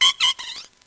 Fichier:Cri 0531 NB.ogg